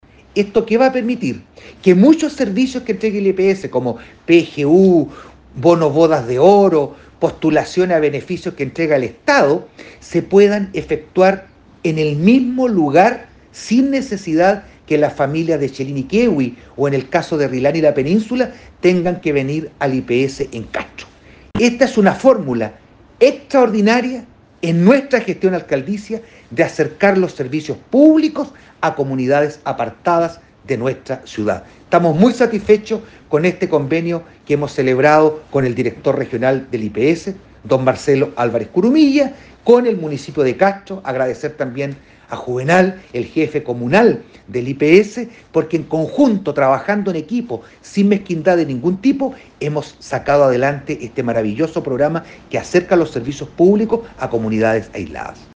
Además, el edil se refirió a los trámites que se podrá realizar en las islas gracias a este convenio:
ALCALDE-VERA-IPS-02.mp3